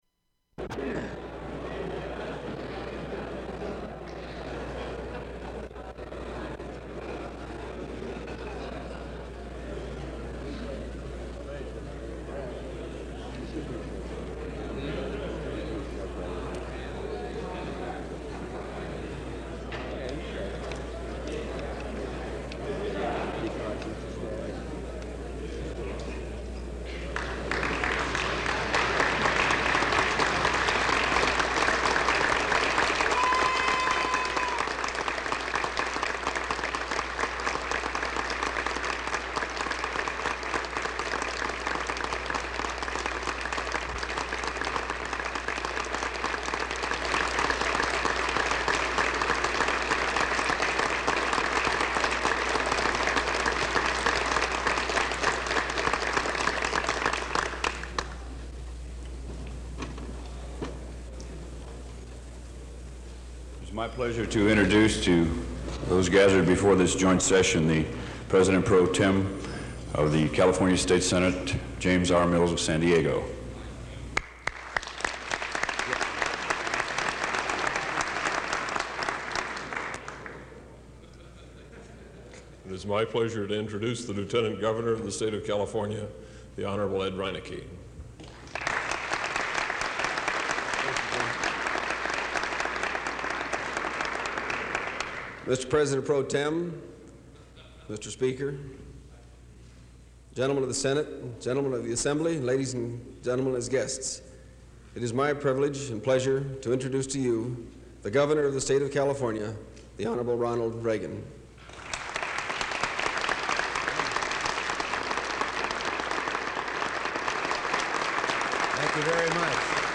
Random chatting
Long applause
Form of original Open reel audiotape